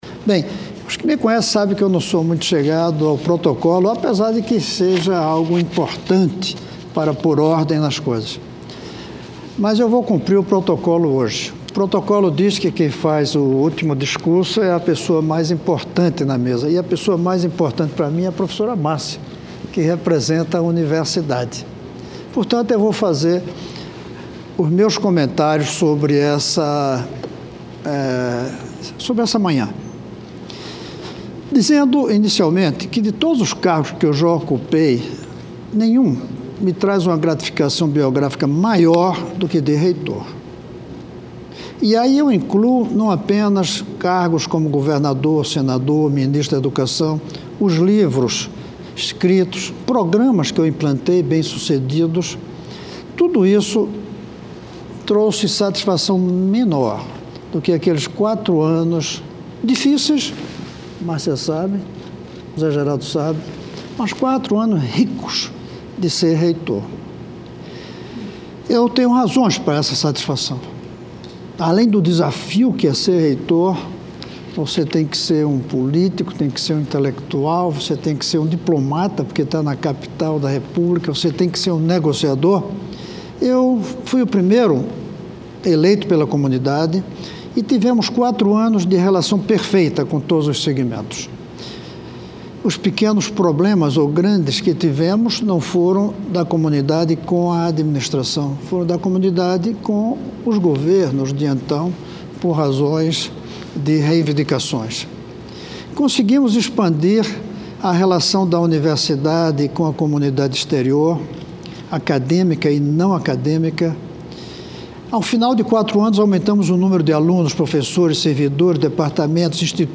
Sessão Especial
Pronunciamento do senador Cristovam Buarque